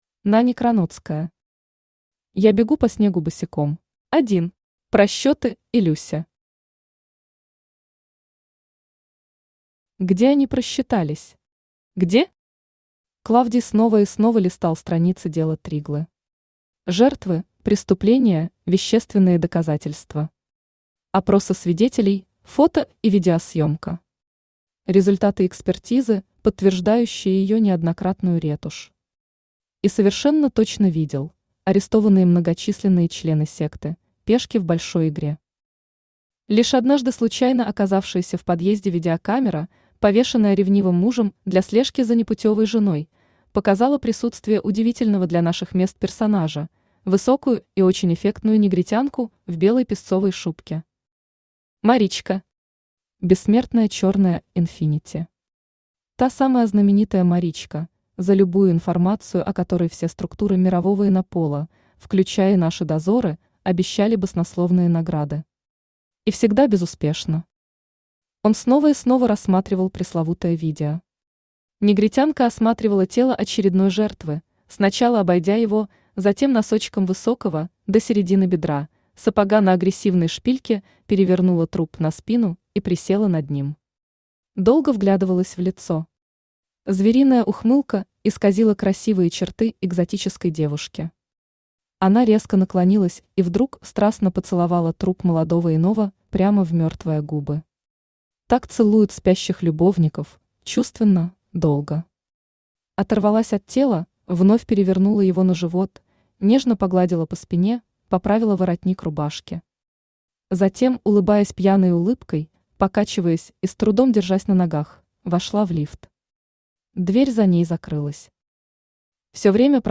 Аудиокнига Я бегу по снегу босиком | Библиотека аудиокниг
Aудиокнига Я бегу по снегу босиком Автор Нани Кроноцкая Читает аудиокнигу Авточтец ЛитРес.